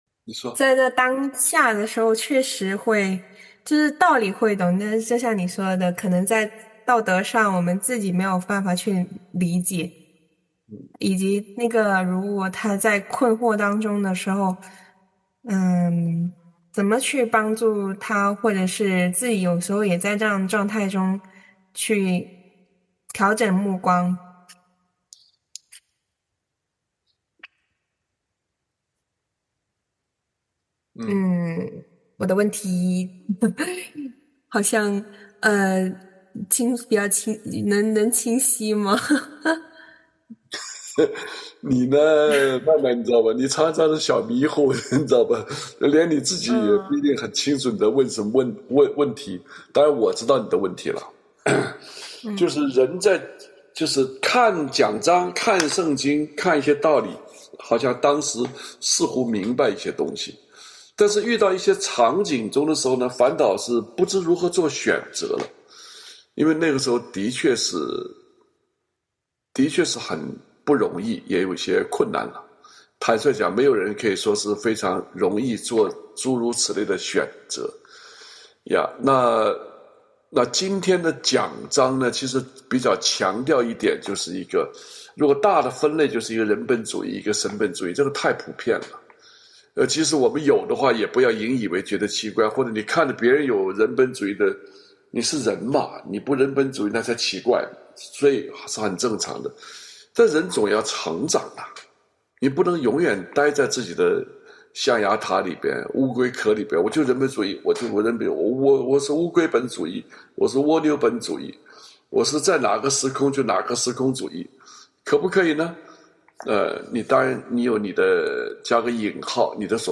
第一场成人主日学（大洋洲亚洲场）